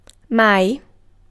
Ääntäminen
UK : IPA : /ˈɔːl.weɪz/